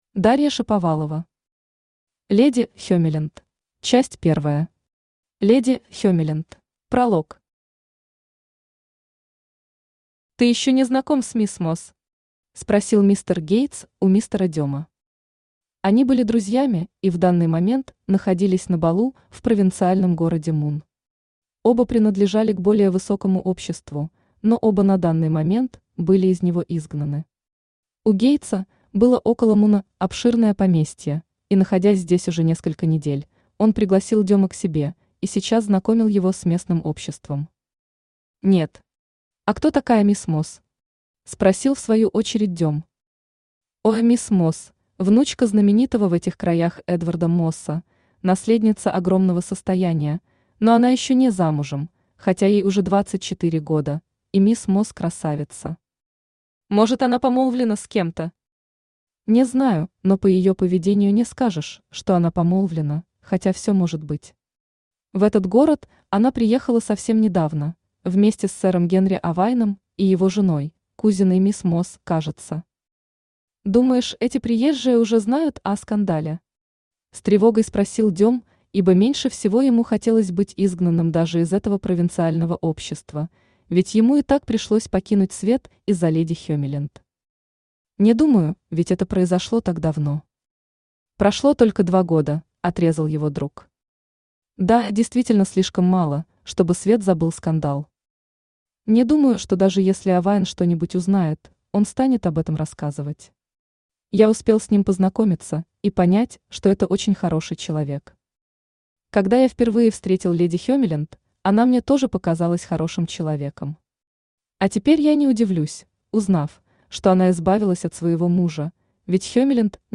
Aудиокнига Леди Хемиленд Автор Дарья Викторовна Шаповалова Читает аудиокнигу Авточтец ЛитРес.